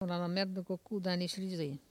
Patois - archive